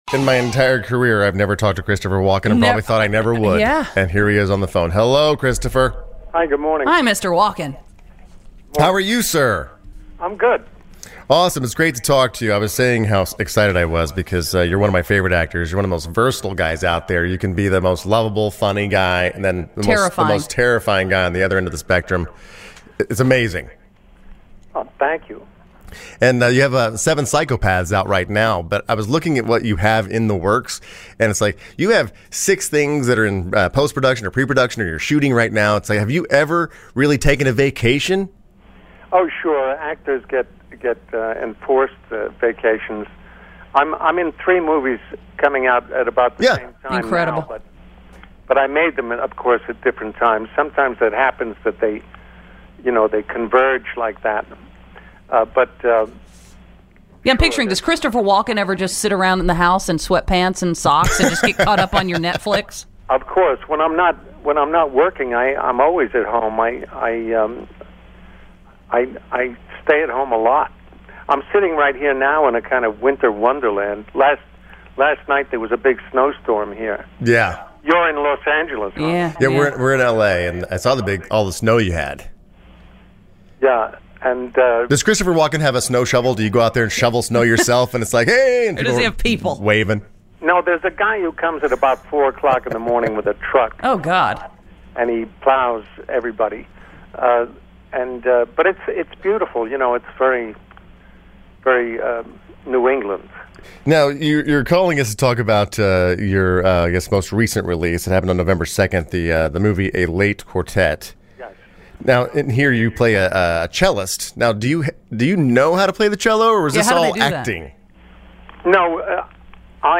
Listen November 08, 2012 - Interview - Christopher Walken - The Heidi & Frank Show